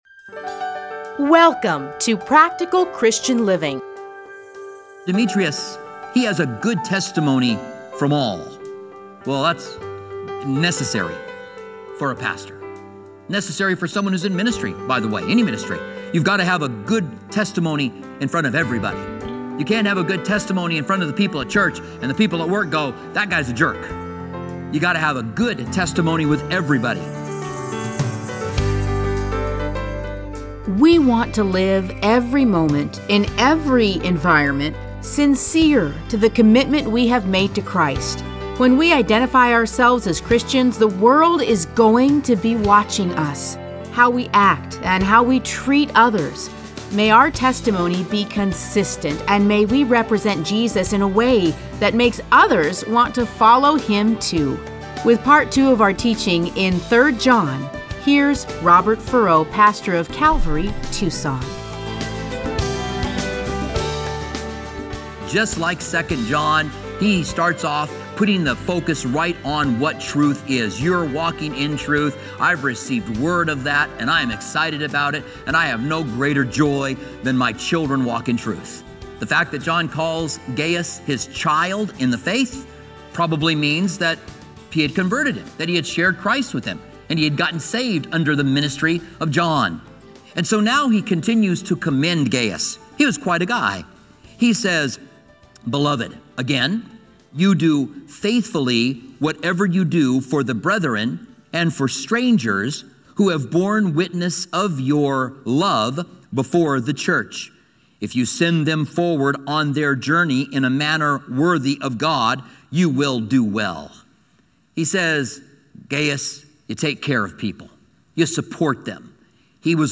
Listen to a teaching from 3 John 1-15.